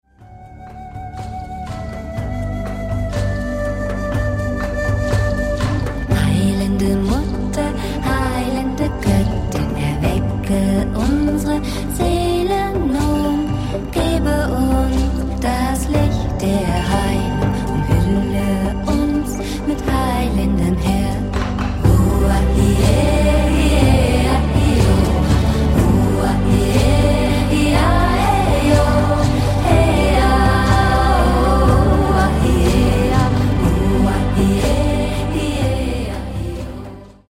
Mediationen, Channelings und Chantings.